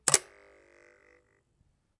刀片振动和故障 " Knive3
描述：记录的刀片声音。
Tag: 刀片声 单击 打击乐器 录音 毛刺 叶片 振动 现场录音 拍摄 声音